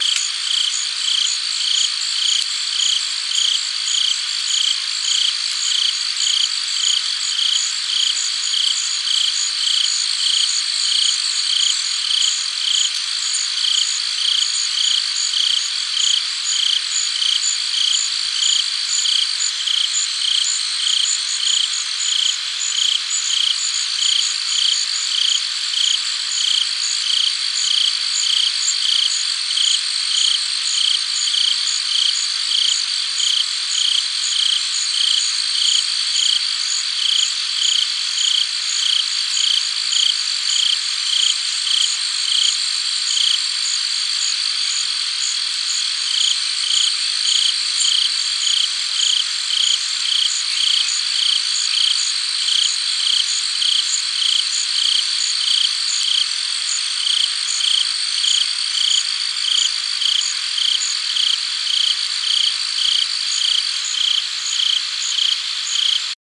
壮观的大自然 " AMBIEN T LOO P 河水的田园风光
非常清晰的河水流动的录音，背景是鸟和蟋蟀。 用H4手提录音机在加拿大安大略省的科莫卡现场录制，直接在泰晤士河上录制。
标签： 鸟类 臭虫 啁啾鸣叫 啁啾 干净 小溪 蟋蟀 蟋蟀 领域 现场录音 田野 流淌 涌出 嘶嘶声 嘶嘶声 昆虫 循环 自然 自然 户外 河流 溪流
声道立体声